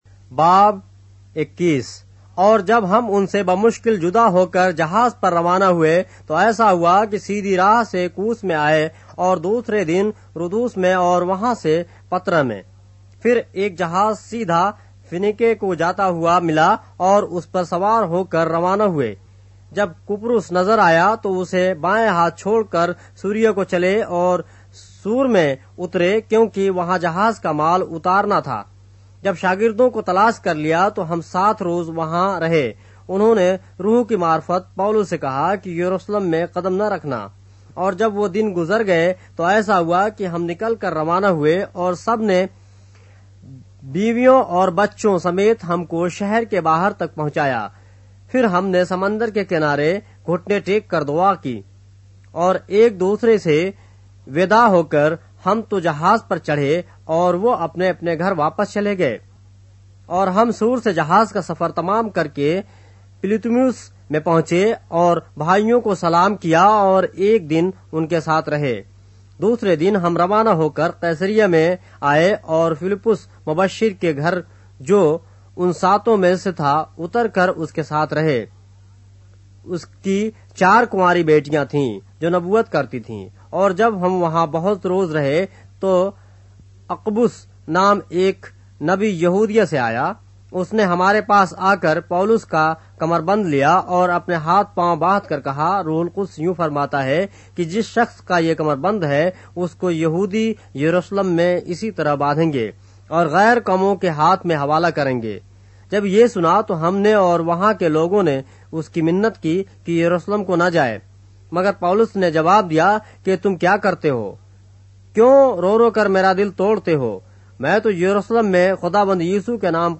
اردو بائبل کے باب - آڈیو روایت کے ساتھ - Acts, chapter 21 of the Holy Bible in Urdu